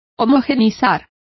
Complete with pronunciation of the translation of homogenized.